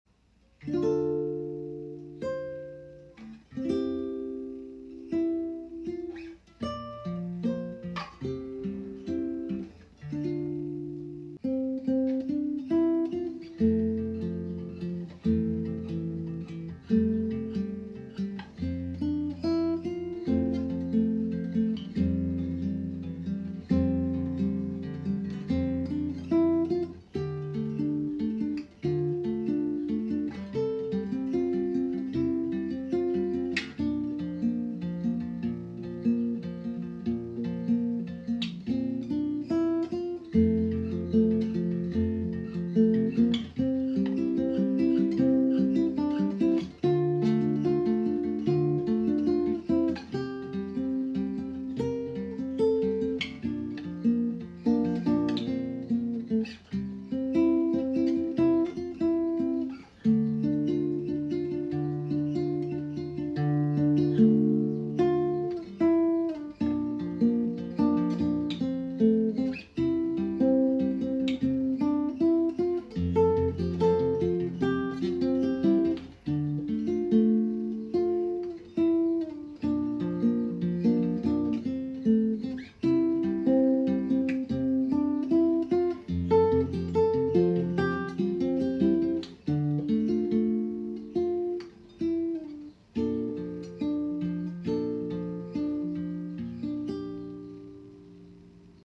guitar arrangement